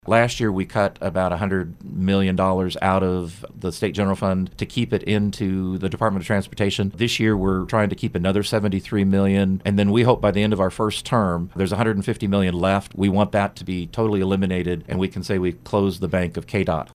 Kansas Lt. Gov. Lynn Rogers stopped by KMAN this morning to discuss various items proposed in Gov. Laura Kelly’s State of the State address and 2020 budget proposal.